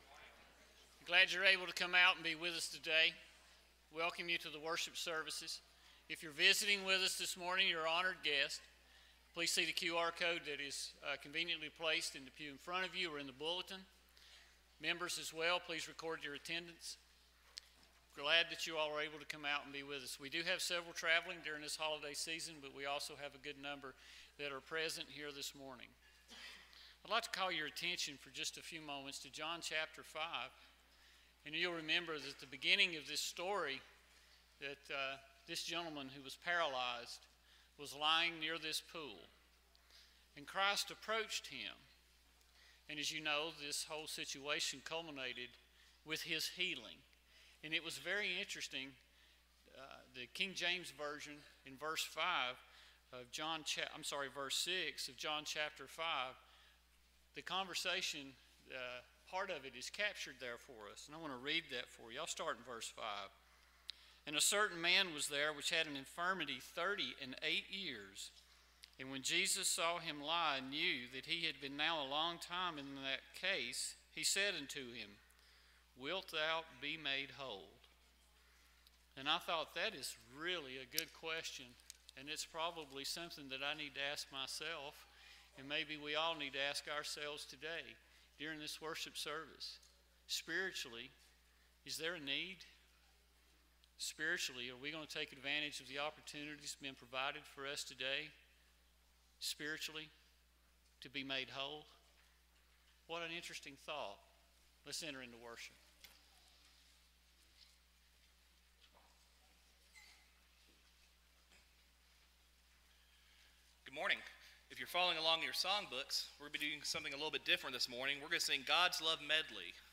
Matthew 25:31, English Standard Version Series: Sunday AM Service